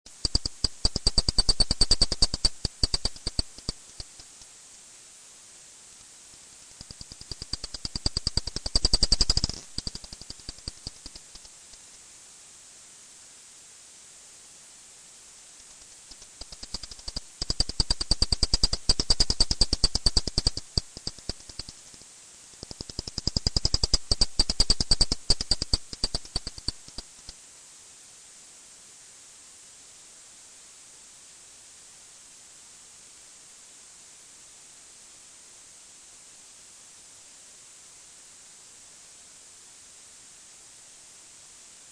An excursion outside, as the bats of Oaken Clough paid a visit and were caught on MP3!
Above: Spectral analysis of bat clicks.
Left: Audible frequencies after the detector has been used.
homebats.mp3